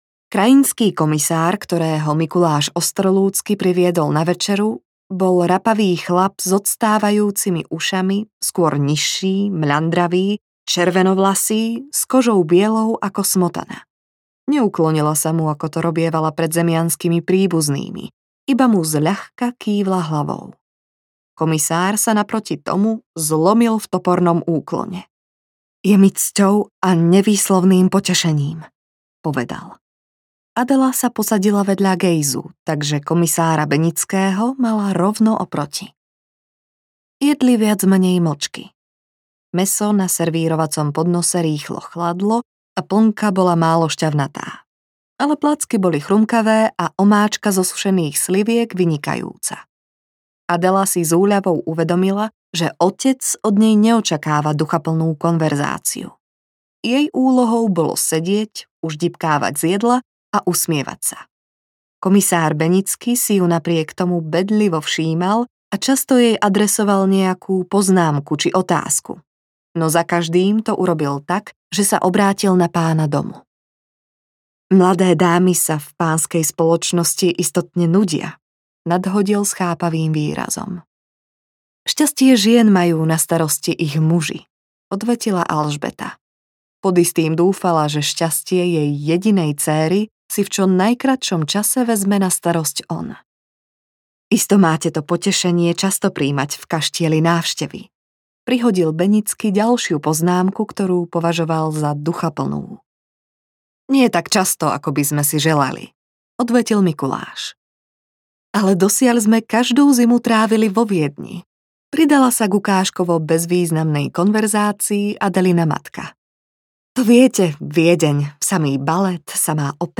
Milenec Adely Ostrolúckej audiokniha
Ukázka z knihy